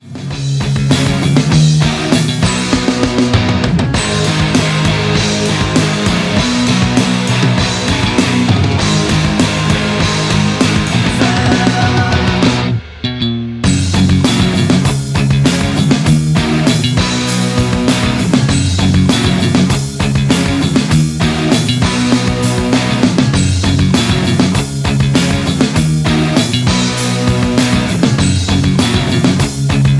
F#
MPEG 1 Layer 3 (Stereo)
Backing track Karaoke
Rock, 1990s